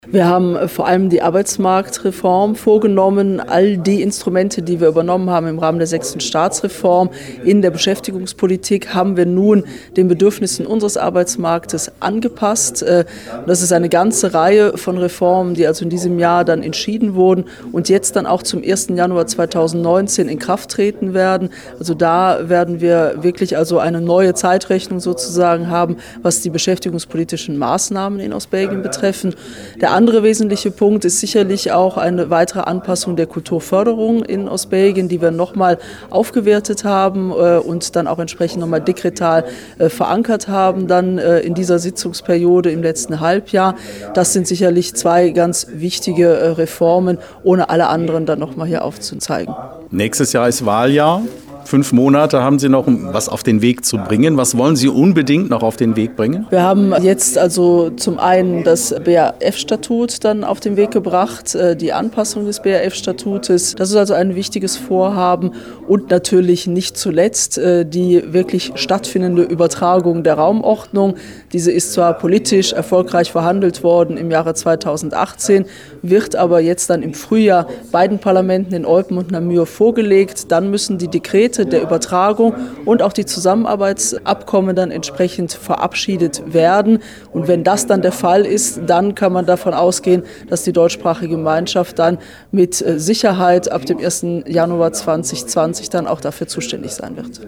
hat mit allen vier Ministern gesprochen